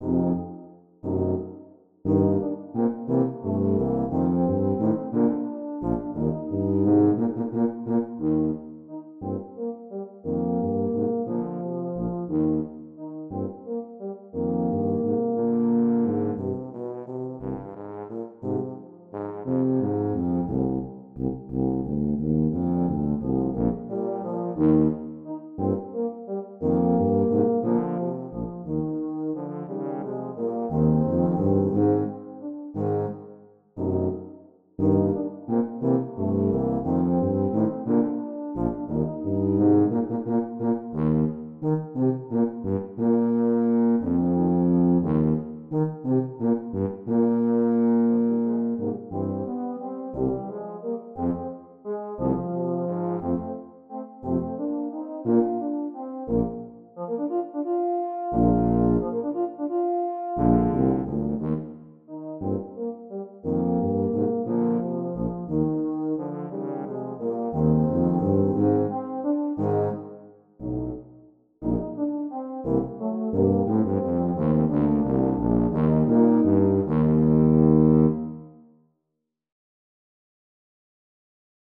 Voicing: Quartet